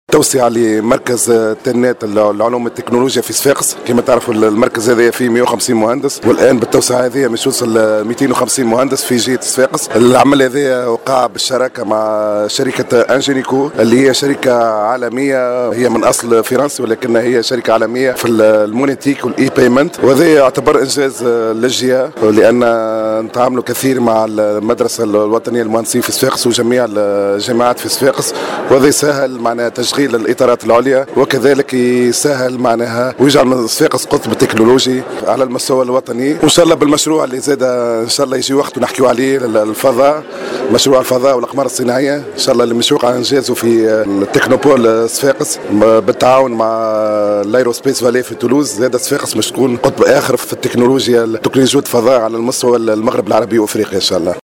أعلنت مجموعة" تلنات هولدينغ" للعلوم والتكنولوجيا عن توسيع مقرها والترفيع في عدد المهندسين العاملين فيها إلى 250 وترمي هذه التوسعة لجعل هذا المقر قادرا على احتواء نشاط البحث والتطوير والخدمات اللازمة وفق ما أكده الرئيس المدير العام للشركة محمد فريخة في تصريح لمراسل الجوهرة "اف ا"م .